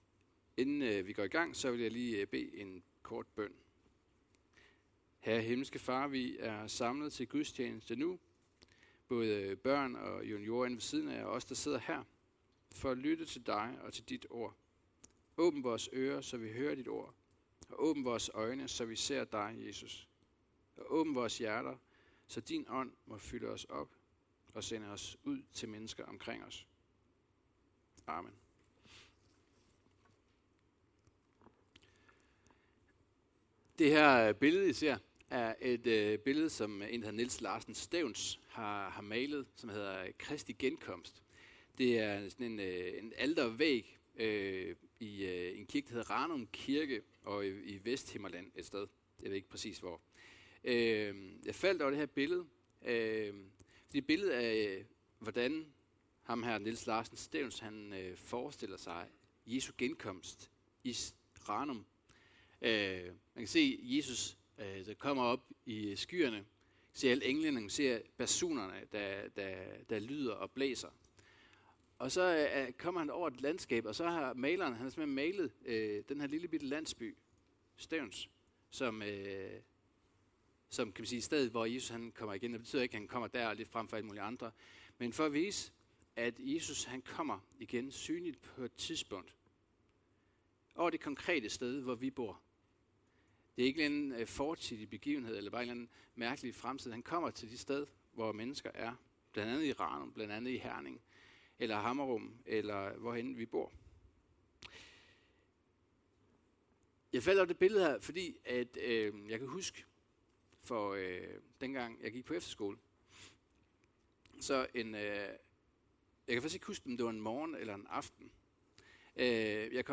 Undervisningsgudstjeneste (del 2 af 4) – 1.Thess 4,13-5,11 – Jesus kommer igen – vi skal ikke være uvidende!